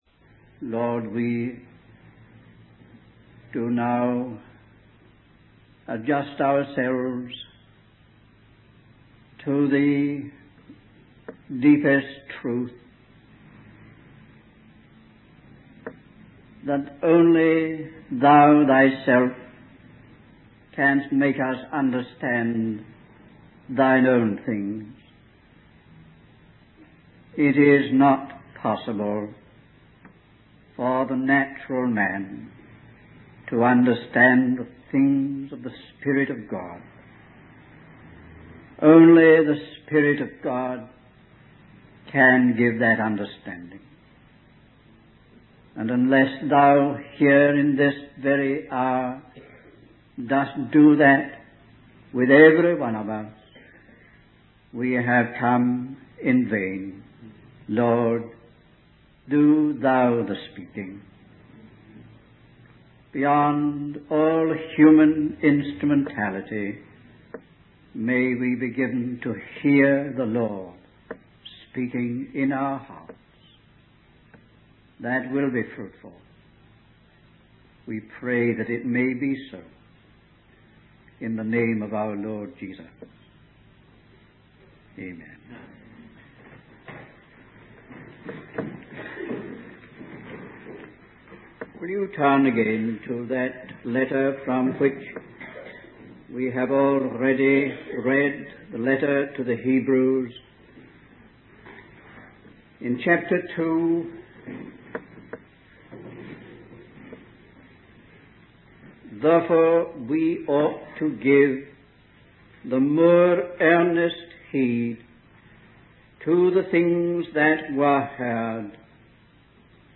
In this sermon, the speaker emphasizes the importance of not missing or neglecting the message being conveyed.